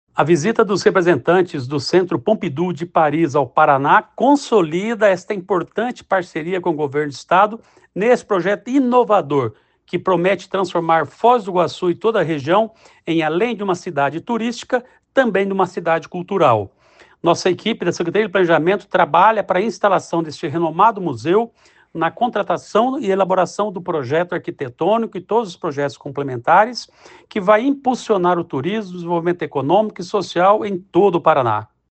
Sonora do secretário do Planejamento, Ulisses Maia, sobre a visita da diretoria do Pompidou ao Paraná